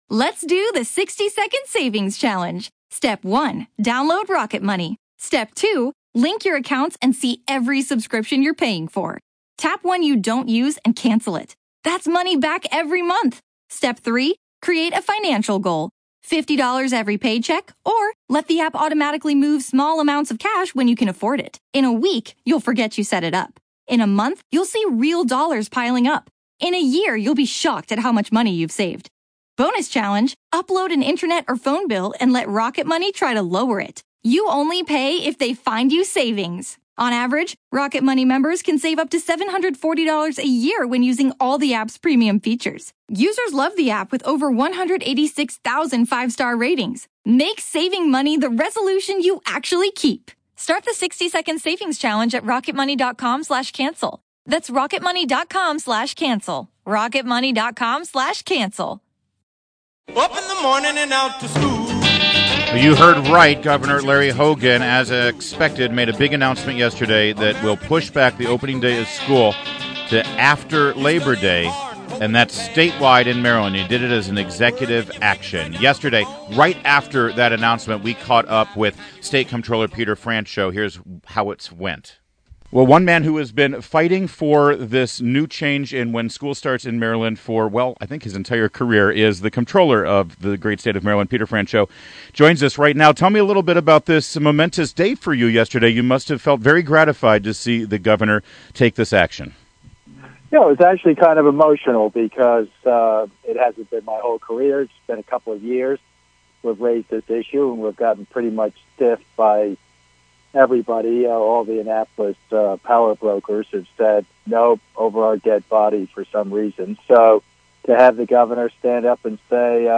WMAL Interview - MD COMPTROLLER PETER FRANCHOT - 09.01.16